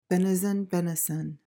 PRONUNCIATION:
(BEN-uh-zuhn/suhn)